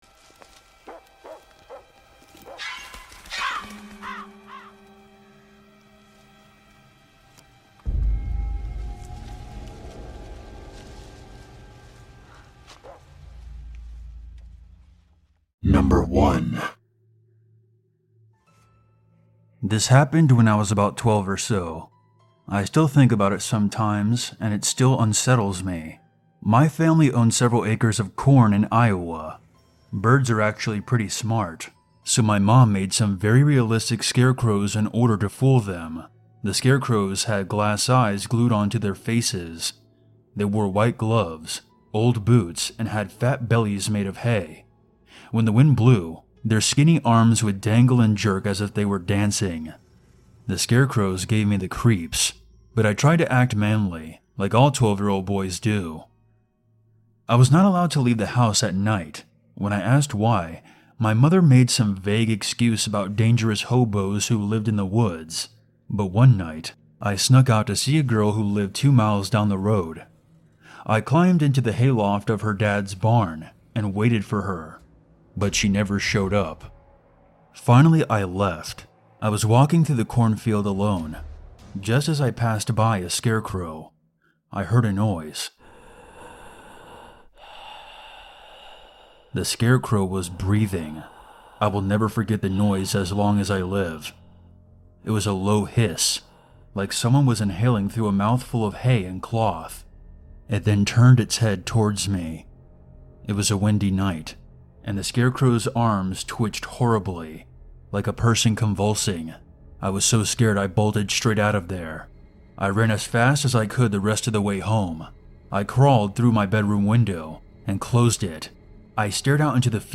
All advertisements are placed exclusively at the beginning of each episode, ensuring complete immersion in our horror stories without interruptions. Experience uninterrupted psychological journeys from start to finish with zero advertising breaks.